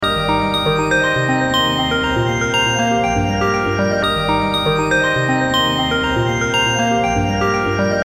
Uplifting Synth Loop
Genres: Synth Loops
Tempo: 120 bpm